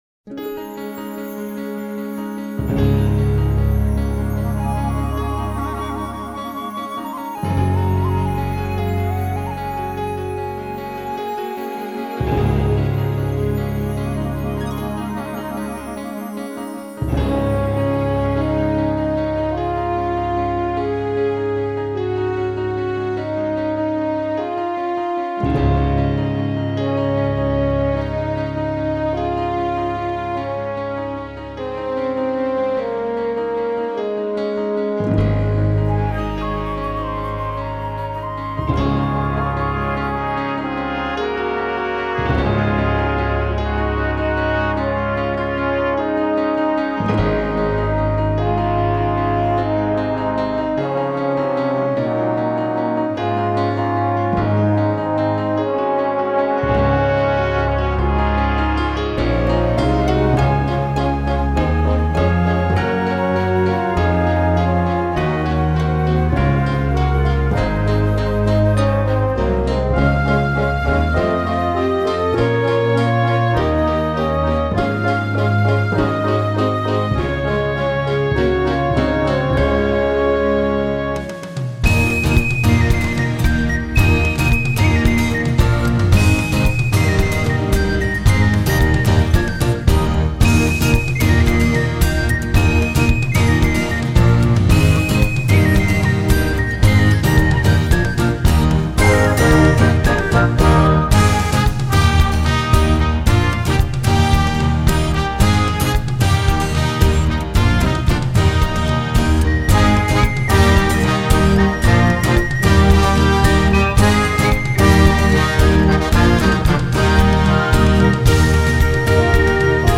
Genre: Gospel & Religious.